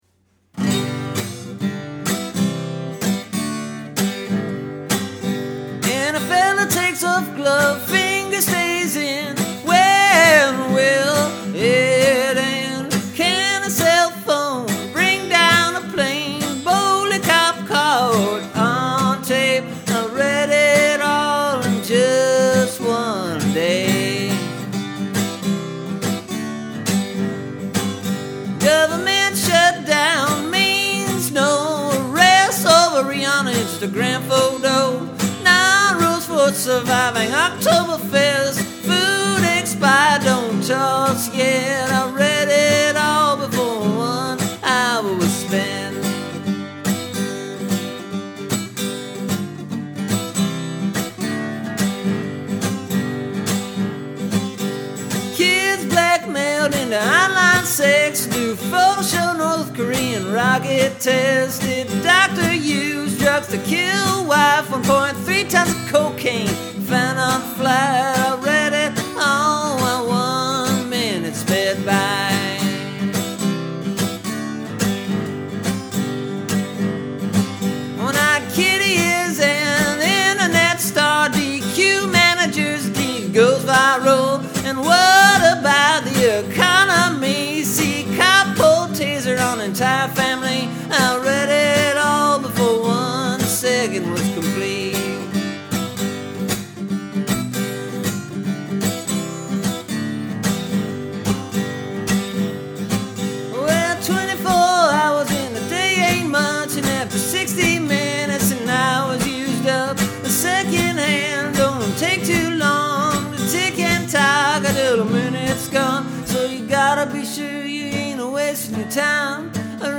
This took me probably about 107 attempts to record.